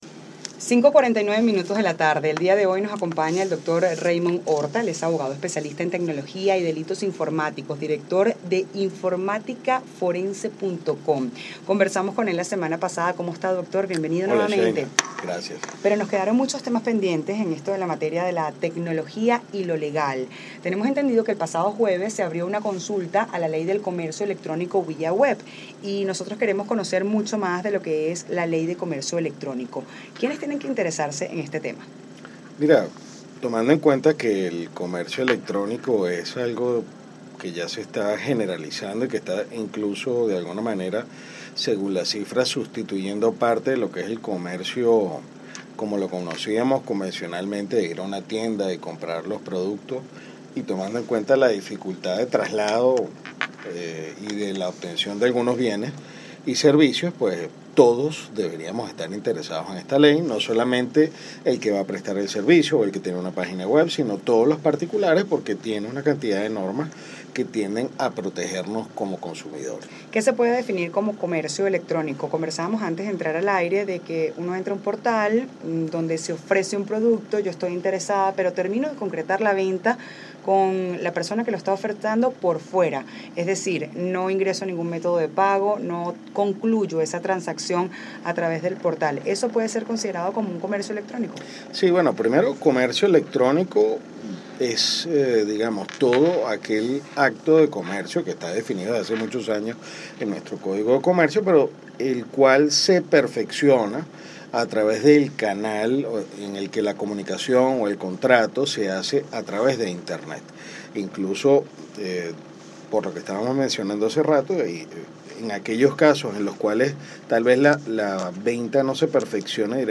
por Unión Radio Venezuela